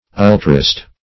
Search Result for " ultraist" : The Collaborative International Dictionary of English v.0.48: Ultraist \Ul"tra*ist\, n. One who pushes a principle or measure to extremes; an extremist; a radical; an ultra.